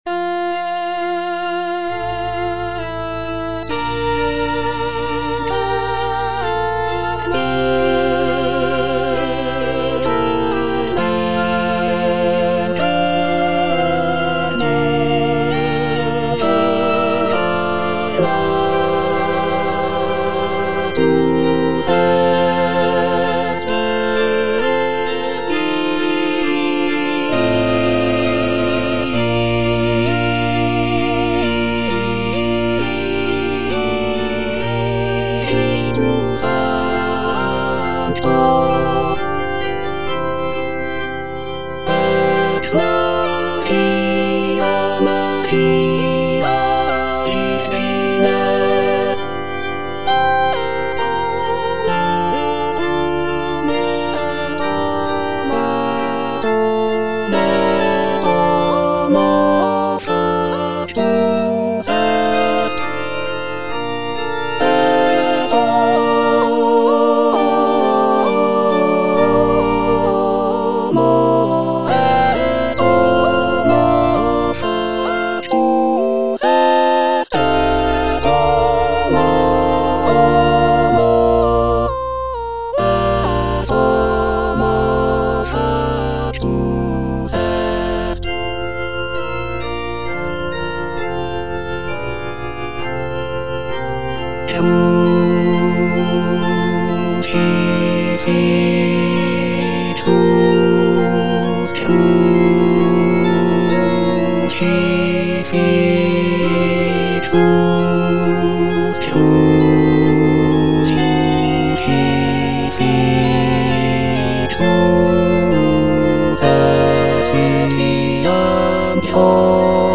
A. BRUCKNER - MISSA SOLEMNIS WAB29 6. Et incarnatus - Bass (Emphasised voice and other voices) Ads stop:
The work showcases Bruckner's signature style of dense harmonies, intricate counterpoint, and expansive orchestration.